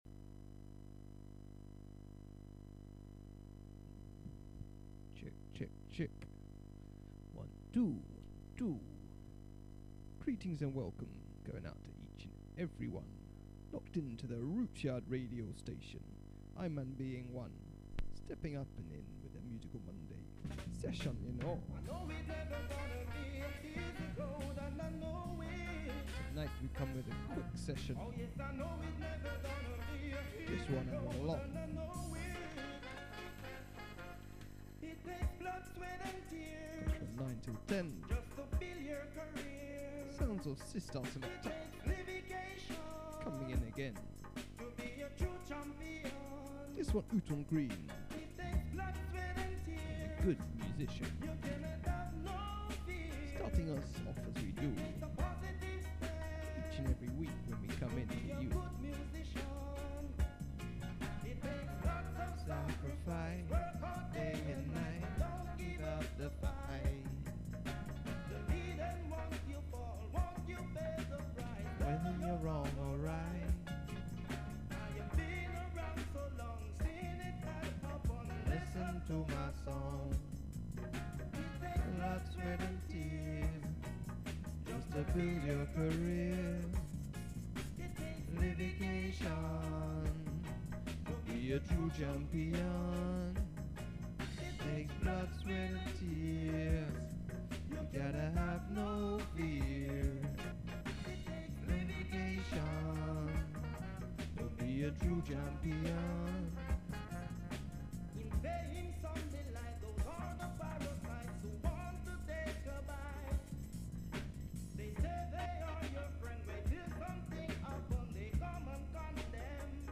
Bass Jam Session